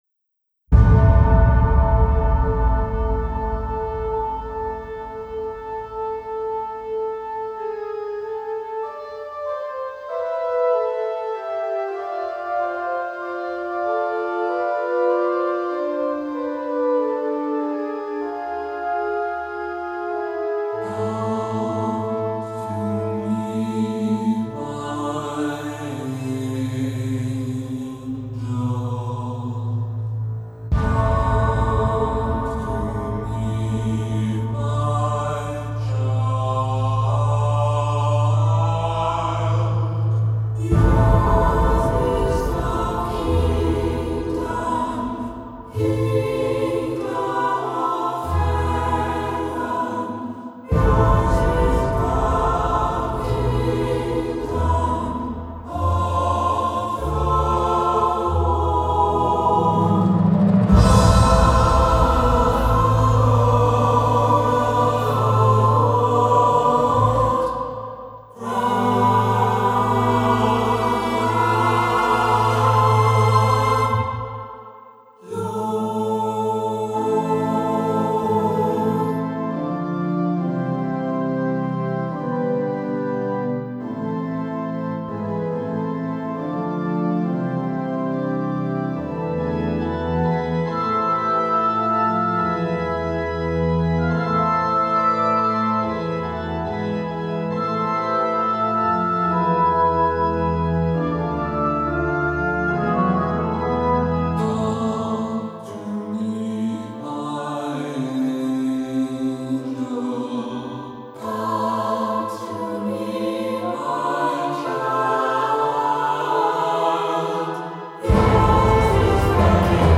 A Gothic Drama
Solo violin and viola